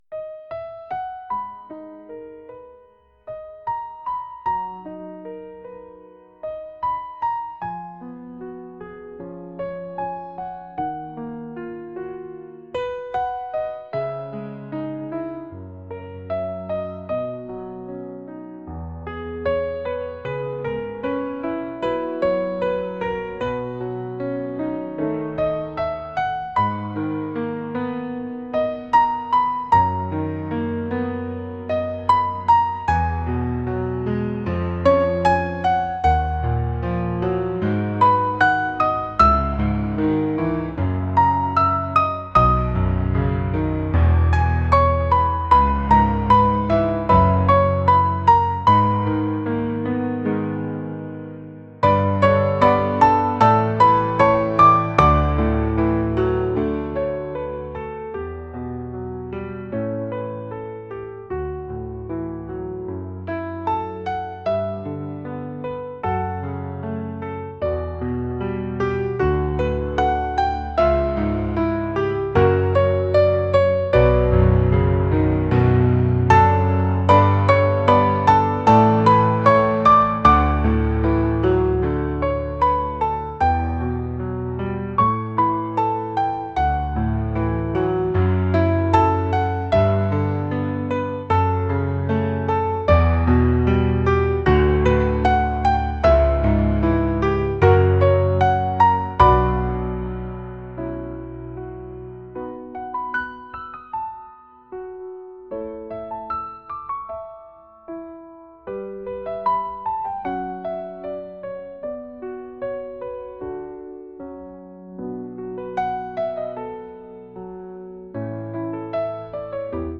pop | acoustic | soulful